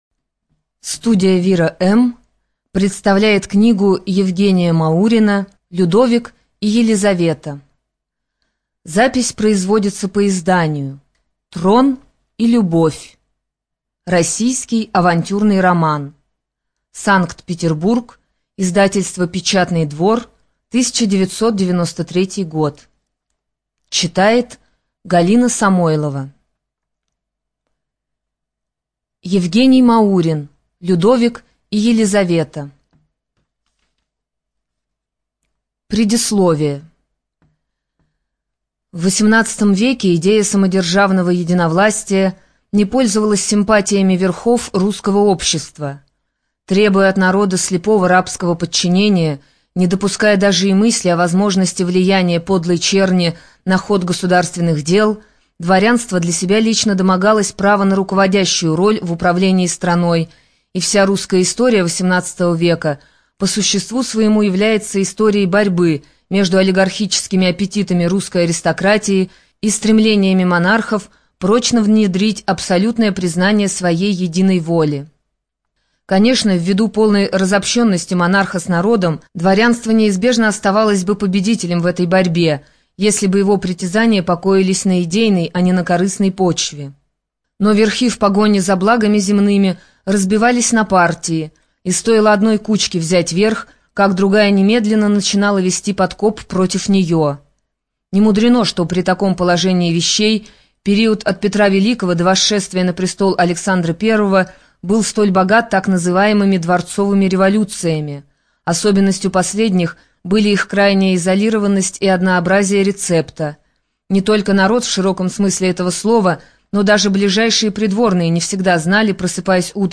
Студия звукозаписиВира-М